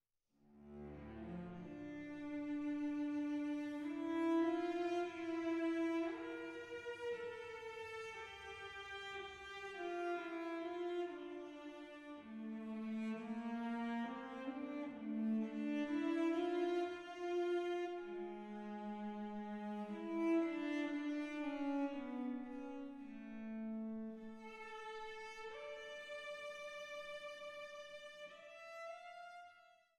Die DREI SUITEN FÜR VIOLONCELL ALLEIN op. 131c